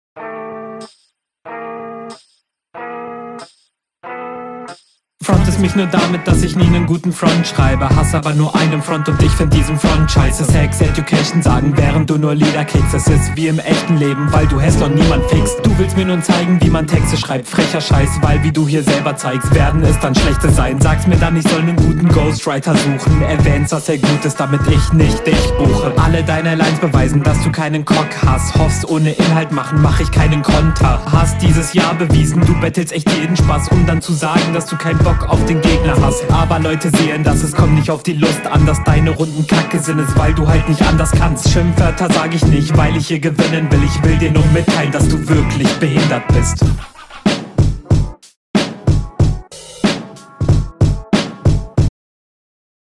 Sound auch in Ordnung und man versteht dich gut.